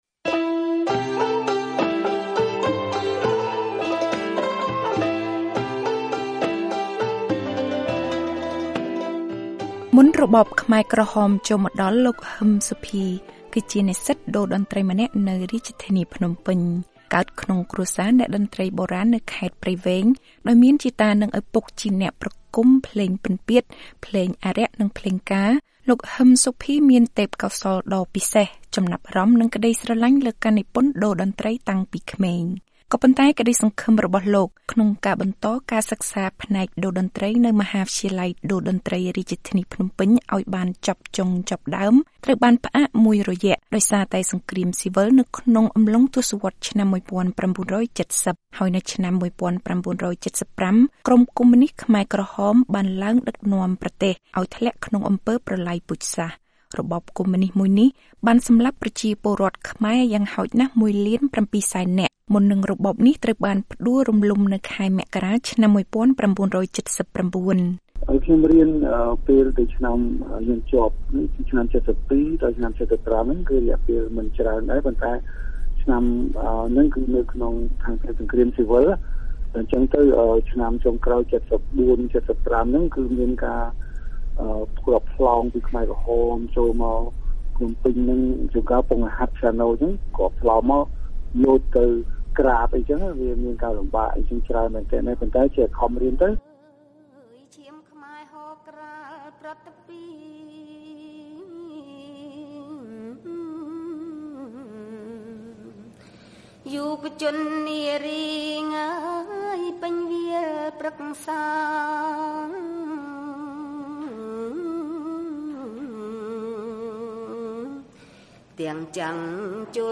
សេចក្តីរាយការណ៍ជាសំឡេង៖ ៤០ឆ្នាំក្រោយរបបខ្មែរក្រហម កម្ពុជាអបអរសាទរការអភិរក្សនិងការវិវត្តថ្មីនៃសិល្បៈខ្មែរ